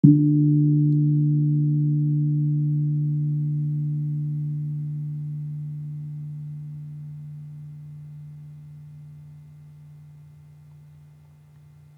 Gong-D2-f.wav